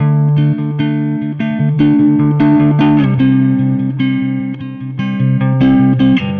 Weathered Guitar 05.wav